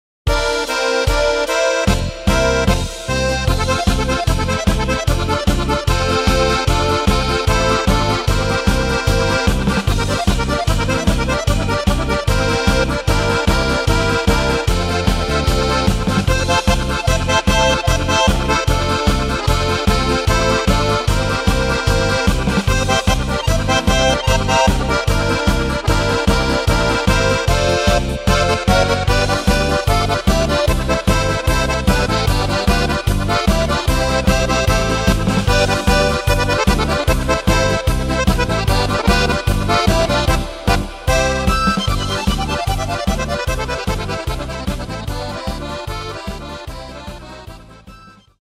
Takt:          2/4
Tempo:         150.00
Tonart:            F
Solo Akkordeon Polka!